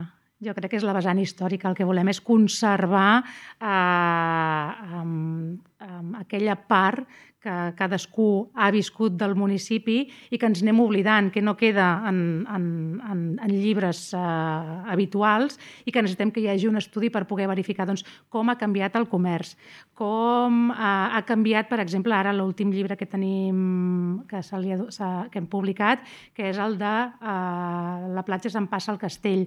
De fet, l’objectiu principal d’aquests premis és conservar el vessant històric de Castell d’Aro, Platja d’Aro i S’Agaró i que no caigui en l’oblit. Així ho explica la regidora de Cultura de l’Ajuntament del municipi, Maria Blanco.